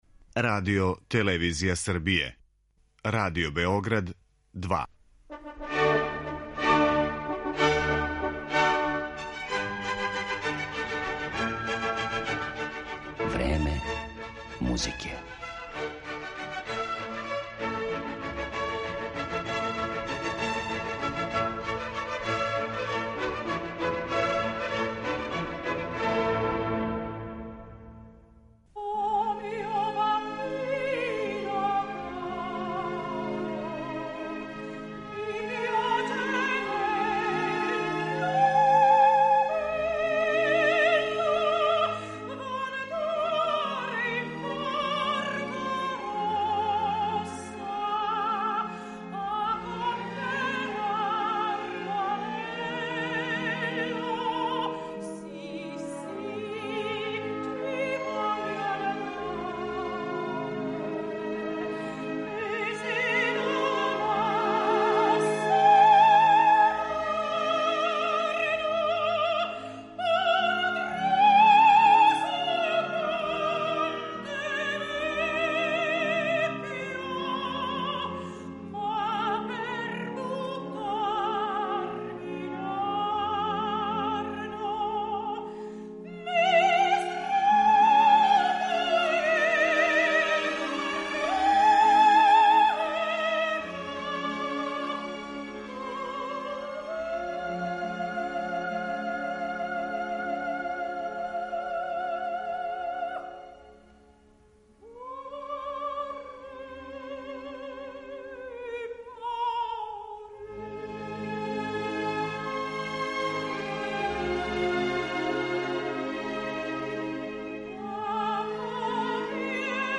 Иако су неке од њених најранијих улога биле и Елза у „Лоенгрину" и Ева у „Мајсторима певачима", сопран ове уметнице идеално је одговарао ликовима из Пучинијевих и Вердијевих опера, попут Тоске, Мими, Виолете или Дездемоне.